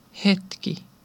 Ääntäminen
IPA: /moˈmɛnt/